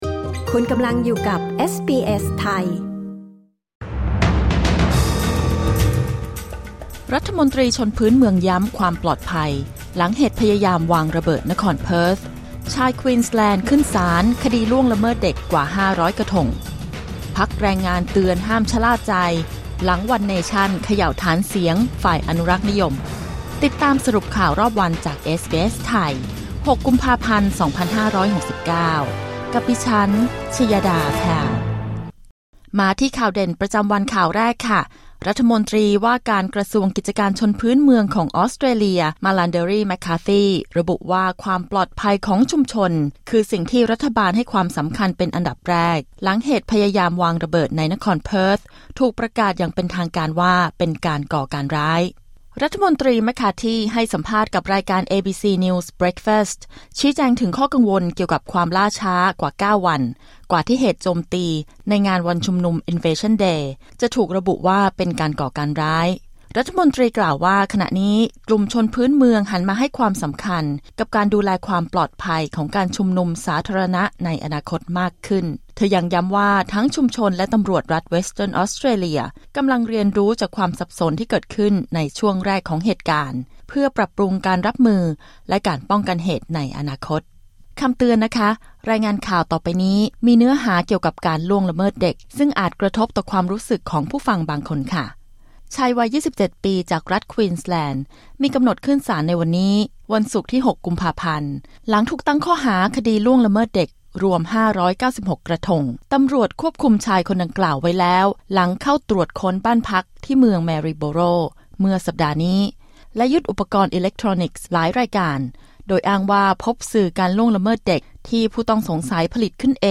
สรุปข่าวรอบวัน 6 กุมภาพันธ์ 2569